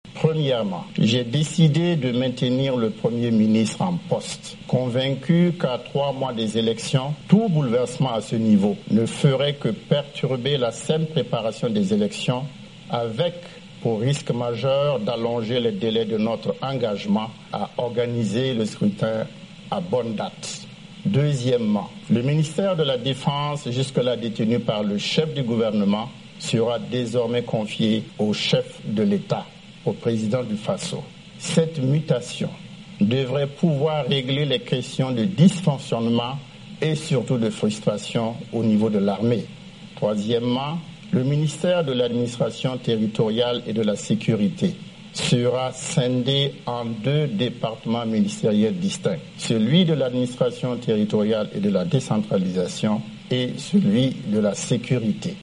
"J'ai décidé de maintenir le Premier ministre en poste", a déclaré M. Kafando dans un discours radiotélévisé solennel, le deuxième en une semaine.
Extrait du discours du president de transition du Burkina Faso Michel Kafando.